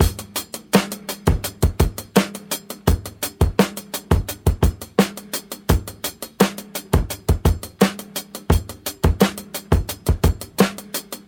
• 85 Bpm High Quality Drum Beat E Key.wav
Free drum groove - kick tuned to the E note. Loudest frequency: 2797Hz
85-bpm-high-quality-drum-beat-e-key-5AG.wav